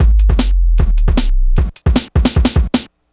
drums05.wav